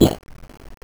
scavengers_soda1.aif